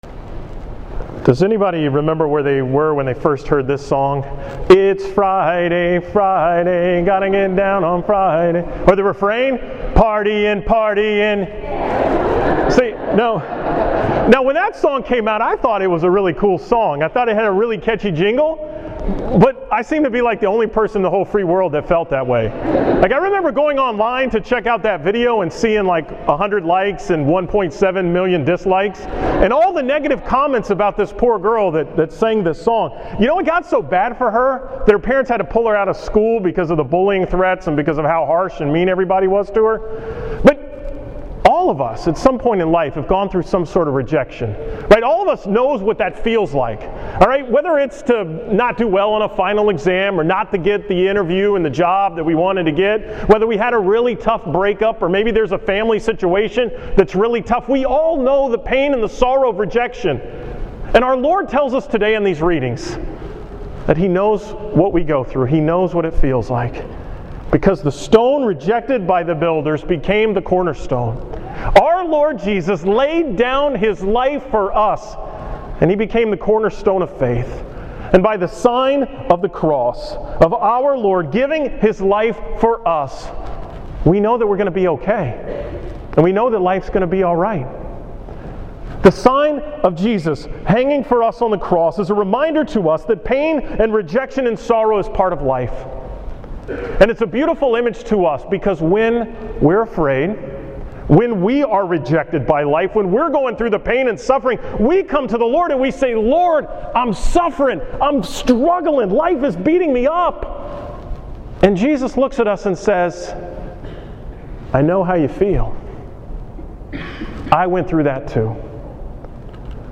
From the 5:30 pm Mass at St. Mary's in College Station on Sunday, April 26, 2015